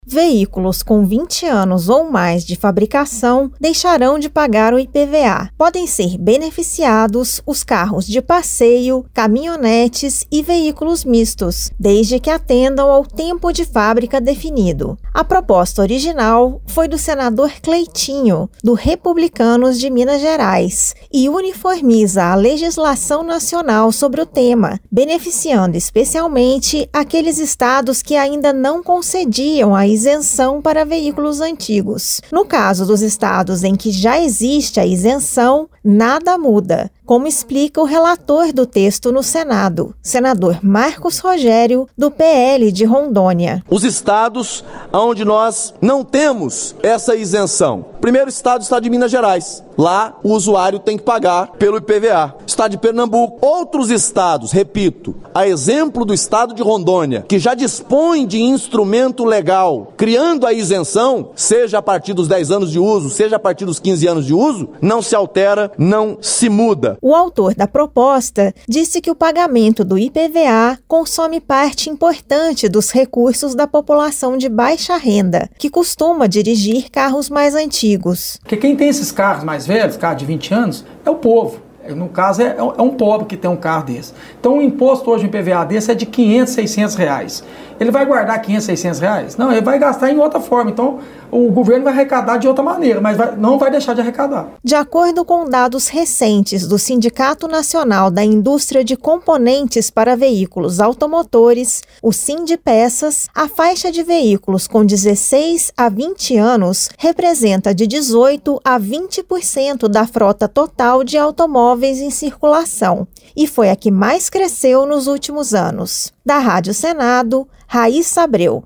Rádio Senado : Notícias.